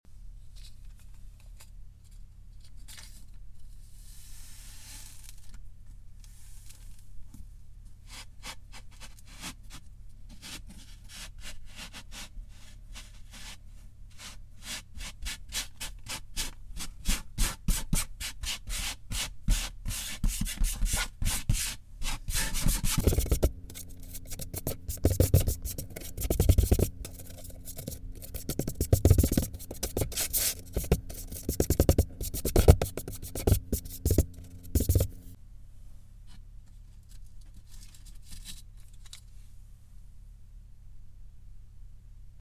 Oddly Satisfying And Relaxing Kinetic